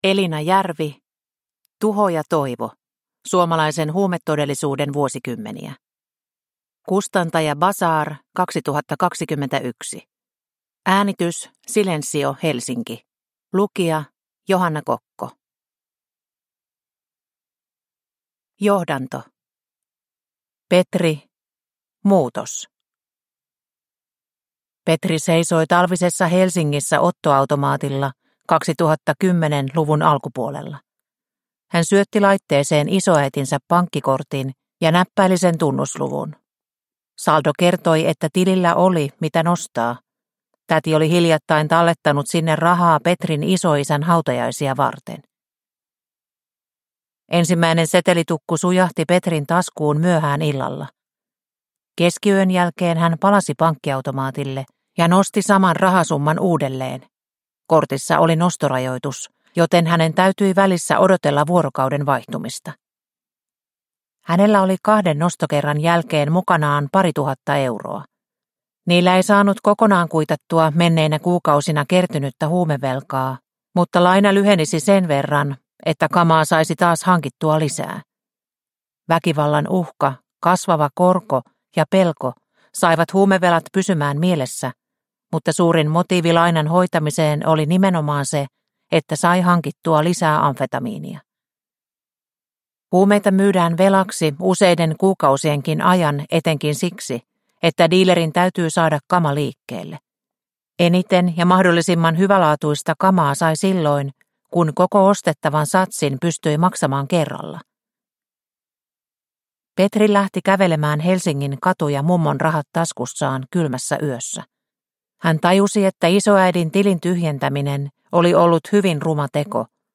Tuho ja toivo – Ljudbok – Laddas ner